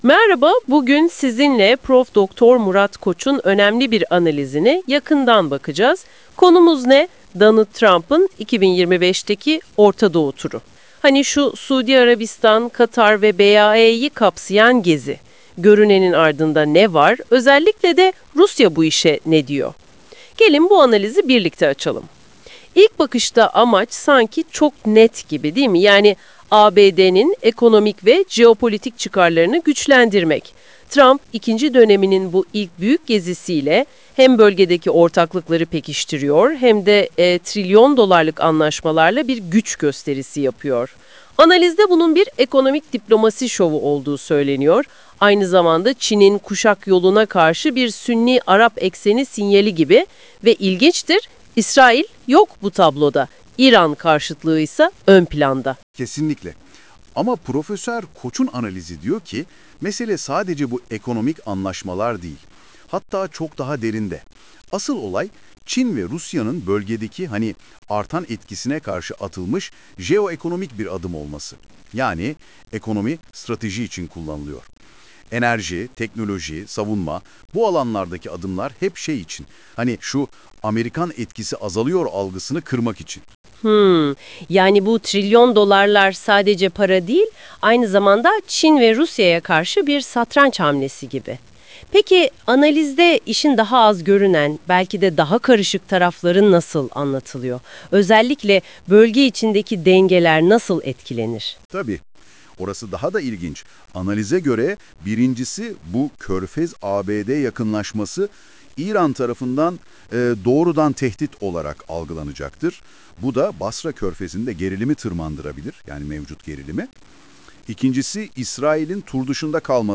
Yapay zeka AI ile yapılan sesli versiyonu